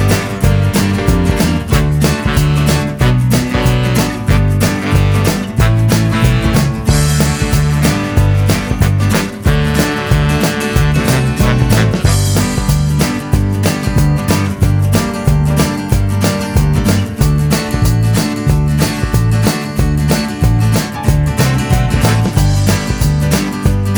No Backing Vocals Rock 'n' Roll 2:59 Buy £1.50